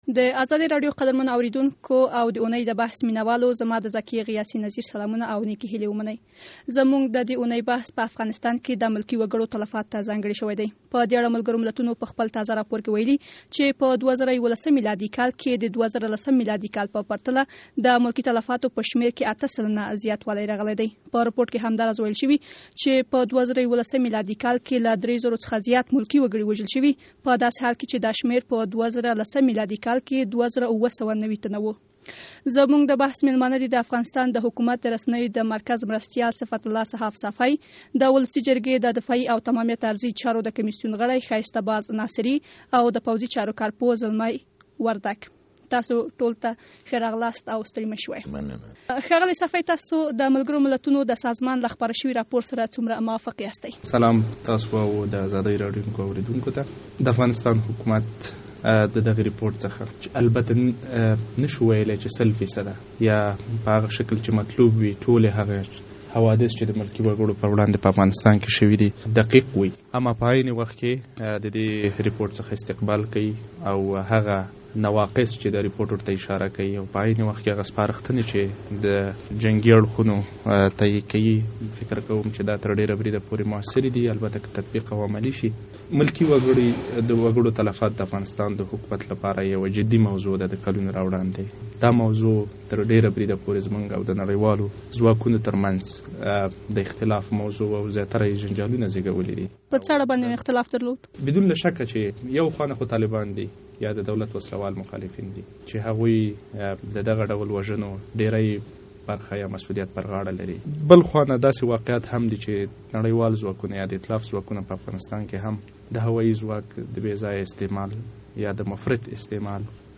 د ملکي تلفاتو په اړه د ازادۍ راډیو ځانګړې بحث